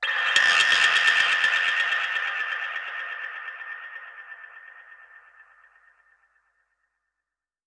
Index of /musicradar/dub-percussion-samples/125bpm
DPFX_PercHit_B_125-09.wav